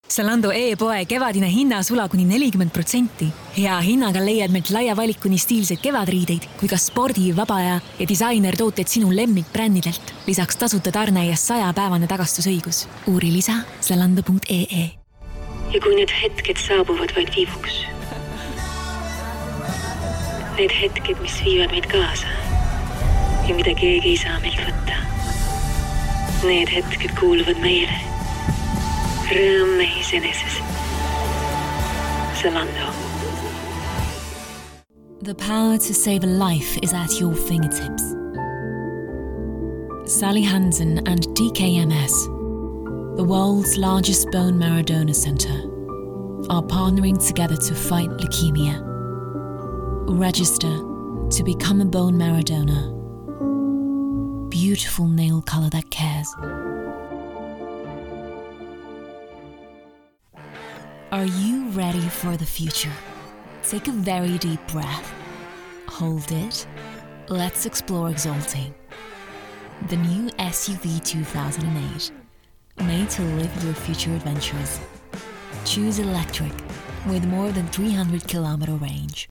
Comercial, Natural, Versátil, Cálida
Comercial
She speaks English and Estonian as her primary languages with an emphasis on RP, Standard American and Estonian delivered in a warm, seductive and smooth tone.